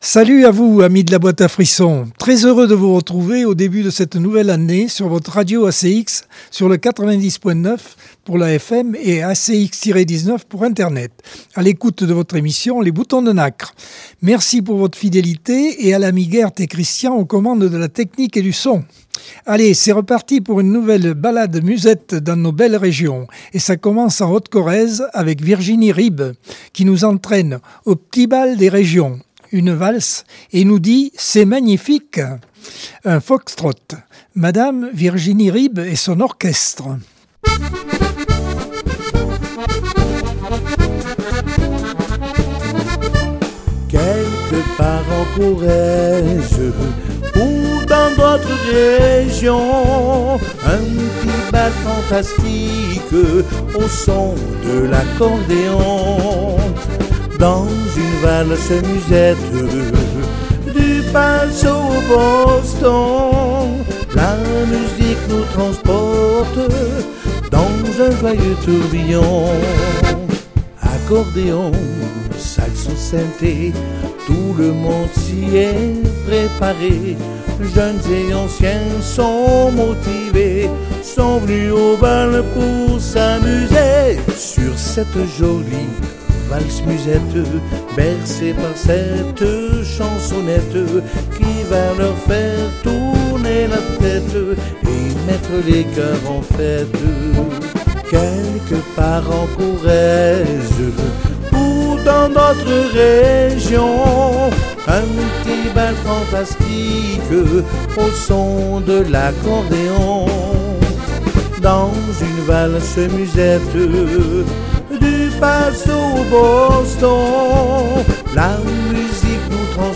Accordeon 2025 sem 02 bloc 1 - Radio ACX